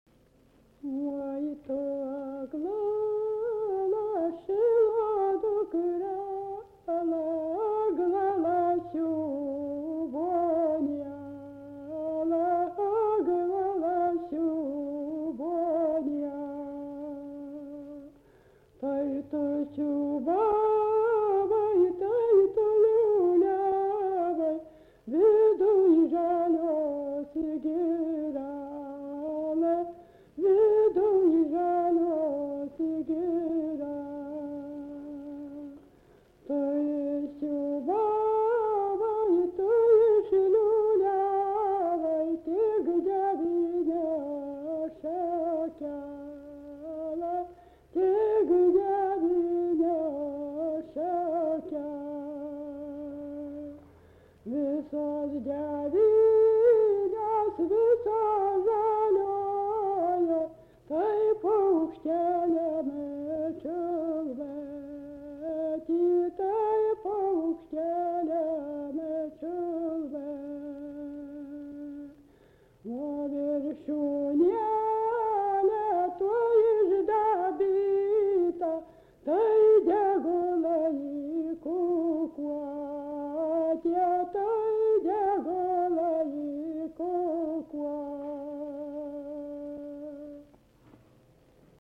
Subject daina
Atlikimo pubūdis vokalinis